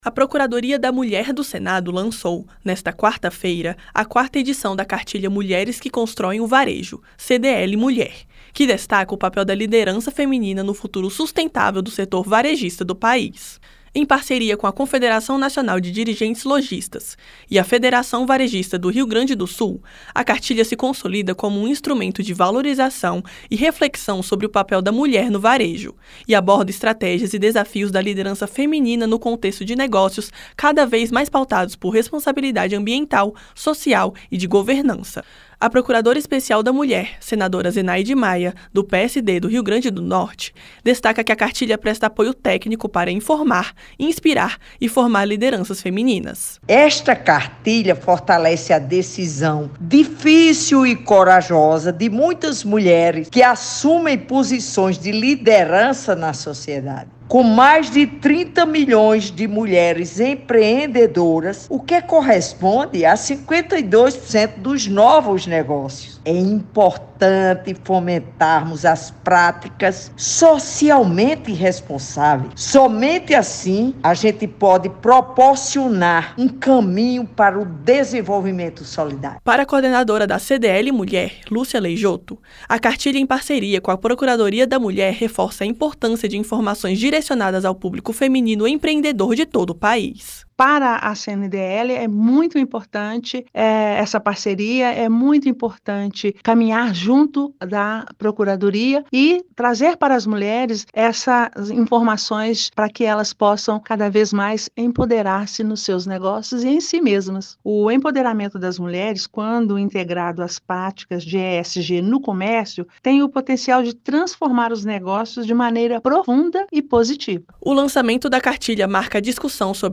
A iniciativa é uma parceria entre a Procuradoria da Mulher do Senado, a Confederação Nacional de Dirigentes Lojistas e a Federação Varejista do Rio Grande do Sul. A procuradora especial da Mulher, senadora Zenaide Maia (PSD-RN) destaca que a cartilha fortalece a decisão difícil e corajosa de muitas mulheres que assumem posições de liderança na sociedade.